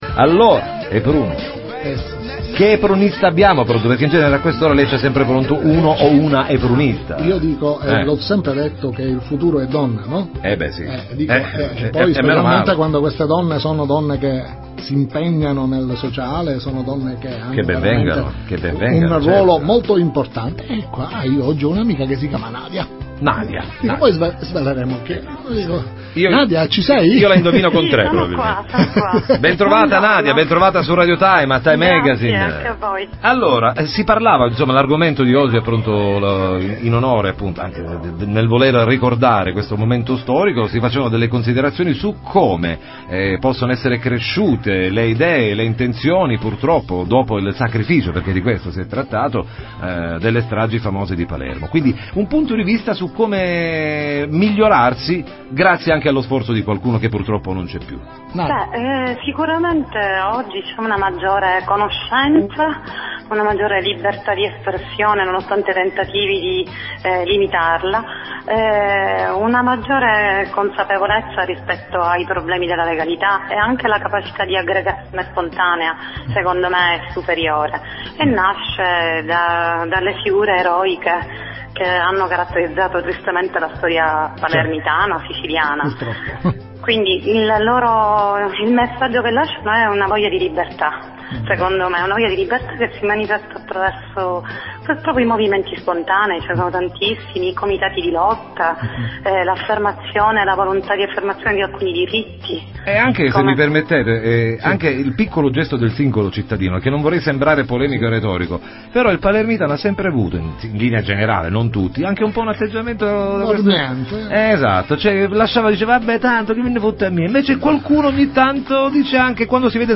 Intervista a Nadia Spallitta su Radio Time